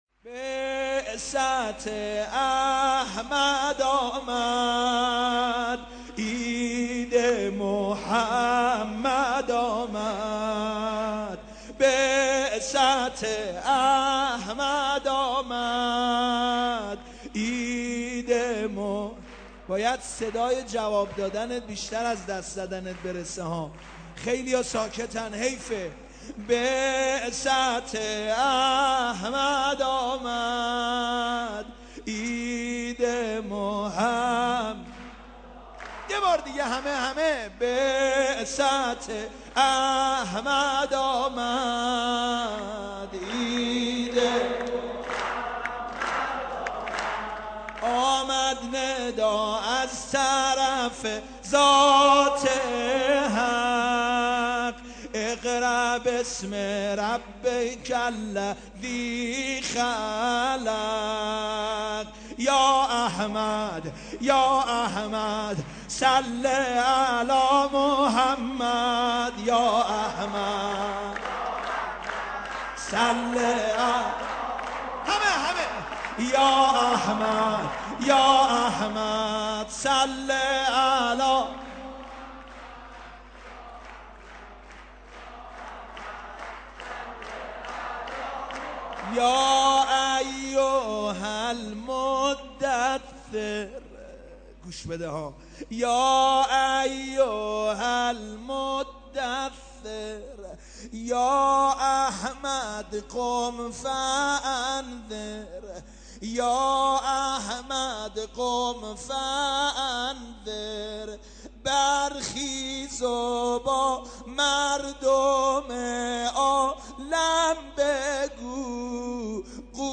عید مبعث